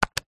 Звук клика кнопки калькулятора